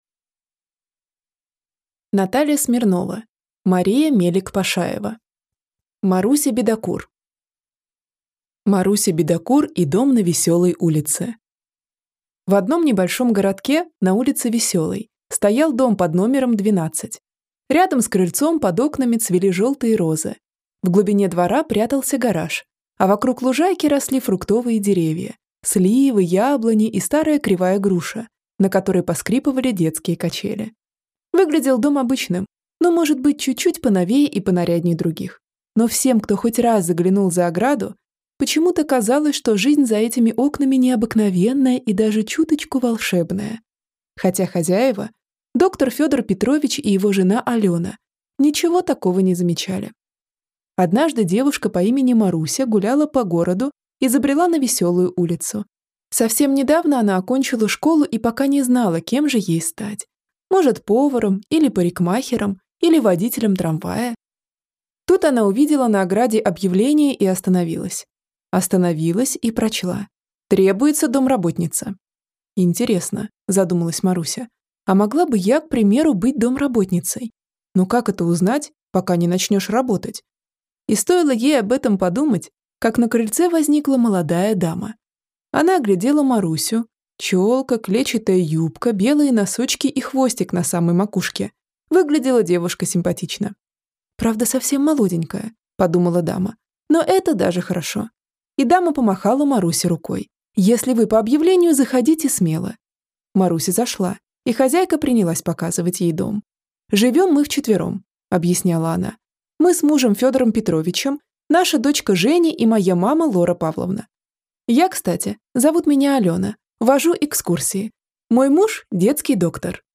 Аудиокнига Маруся Бедокур | Библиотека аудиокниг